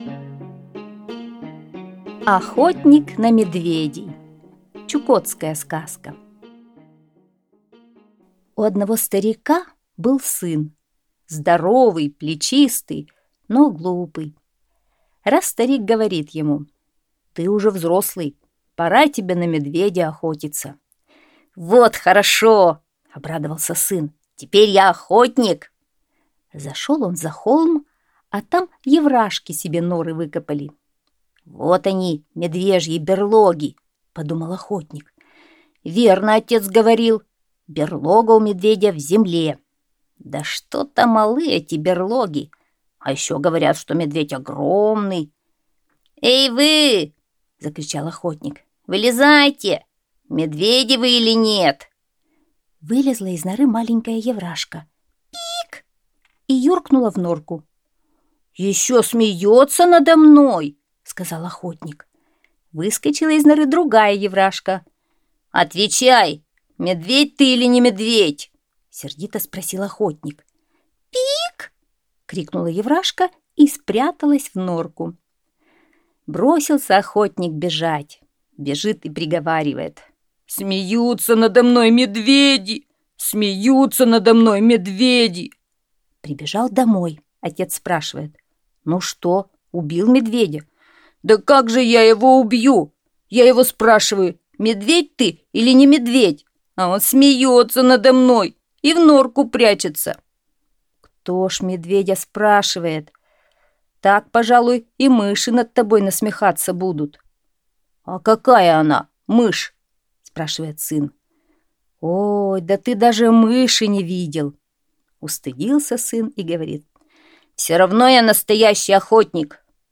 Чукотская аудиосказка